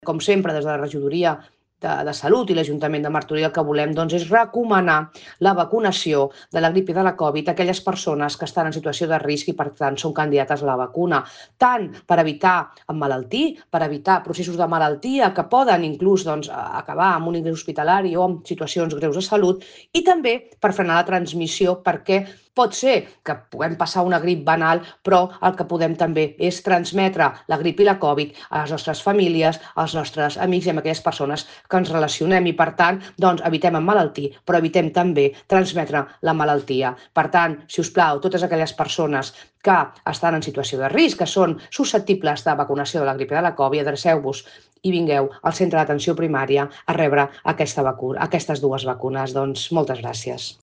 Soledad Rosende, regidora de Salut